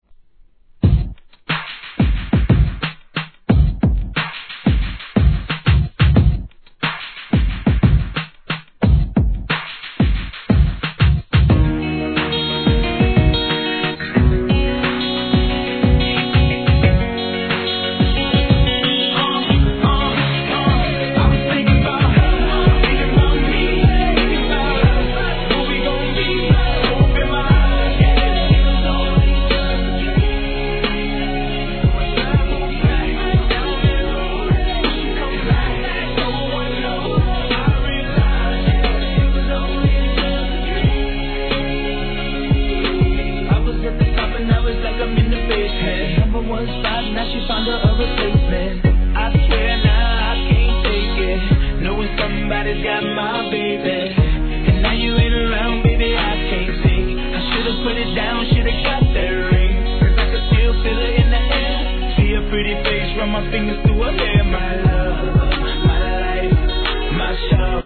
HIP HOP/R&B
[BPM90]  B2.